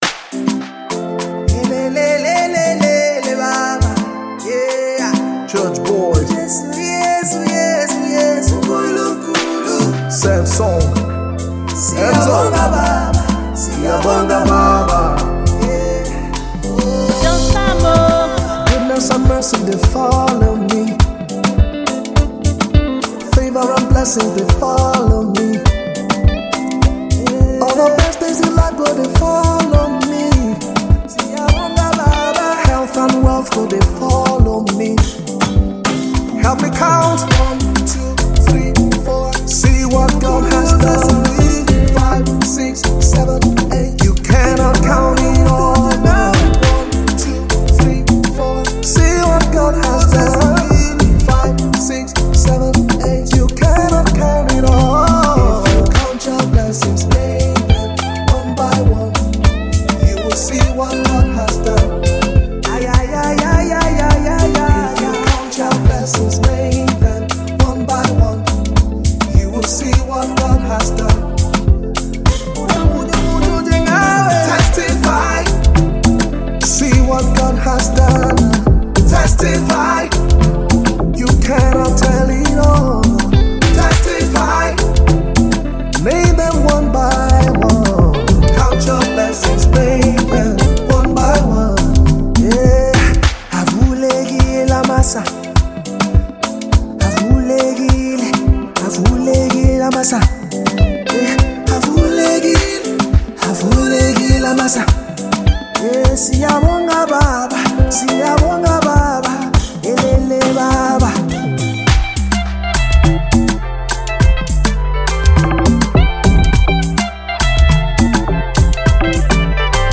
Category: Gospel Music Genre: Afrobeats Released